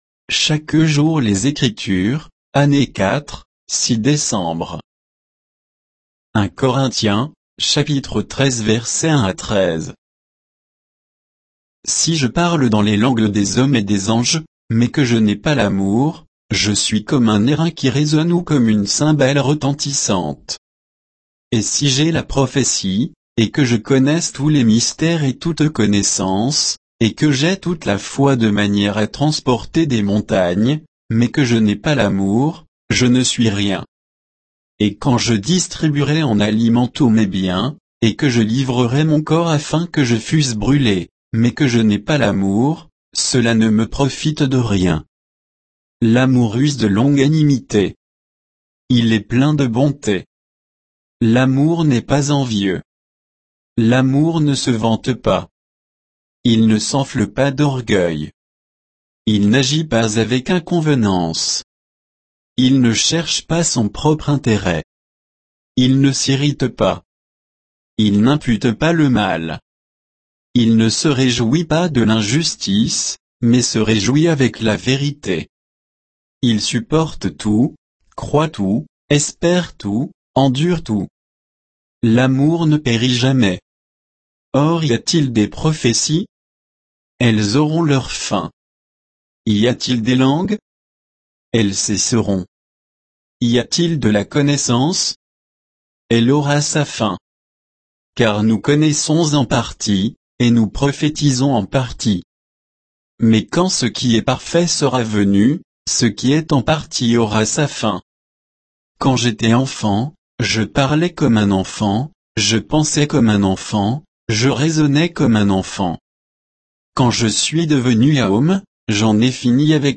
Méditation quoditienne de Chaque jour les Écritures sur 1 Corinthiens 13